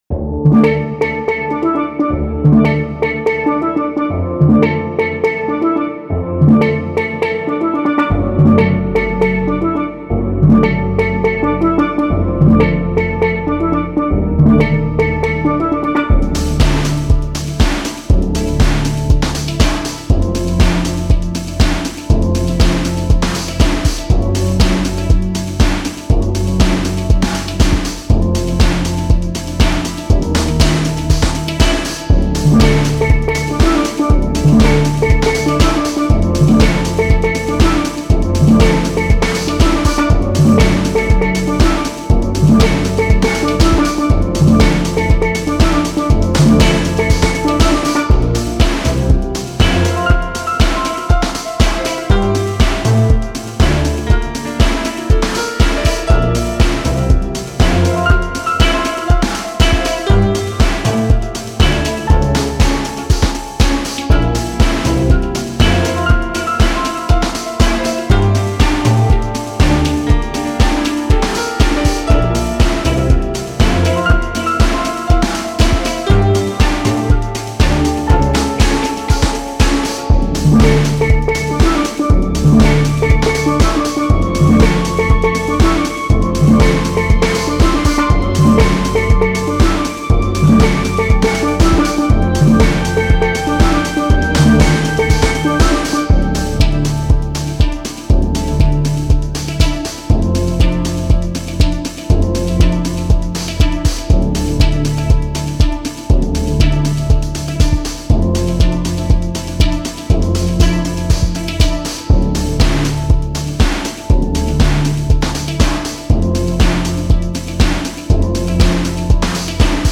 Textured metal and tightened skin beaten in wave after wave